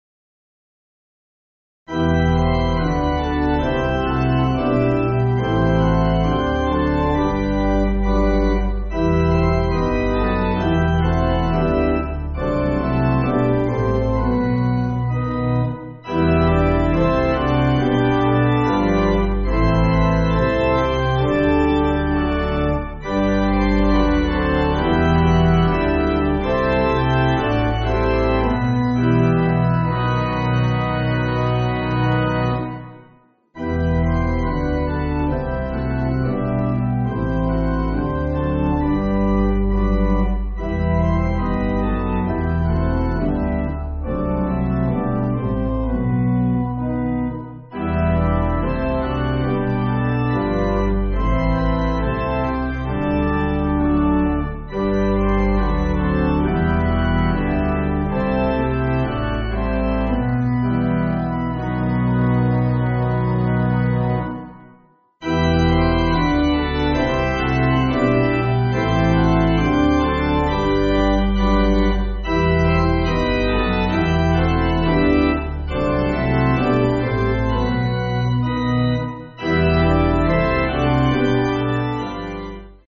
(CM)   3/Bm